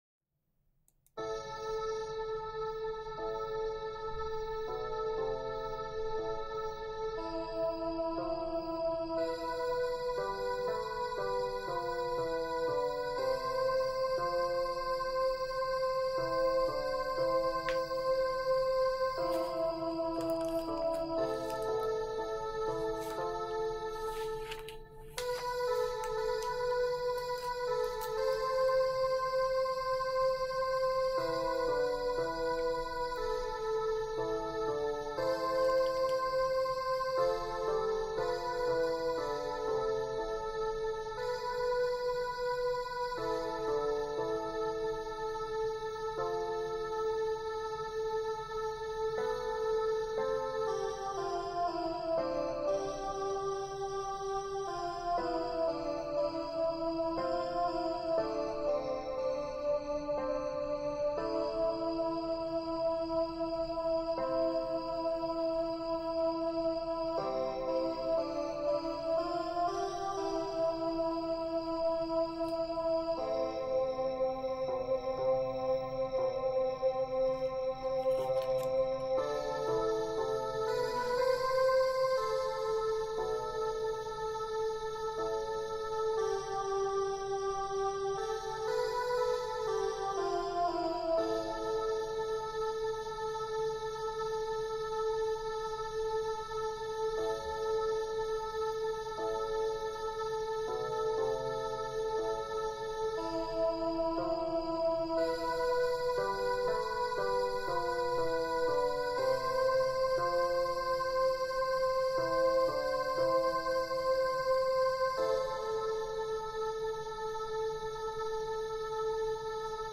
\clef soprano \key c\major \time 3/2
\set Staff.midiInstrument = "pad 4 (choir)"
\set Staff.midiInstrument = "acoustic bass"